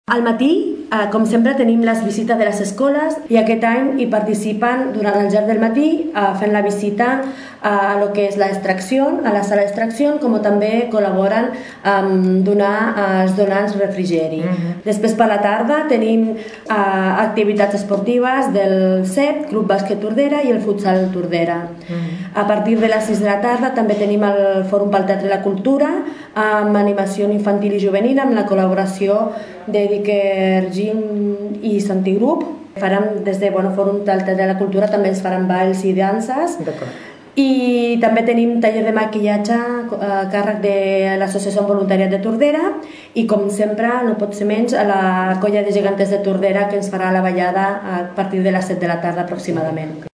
La regidora, Eliana Romera enumera les activitats previstes en la marató de donació de sang.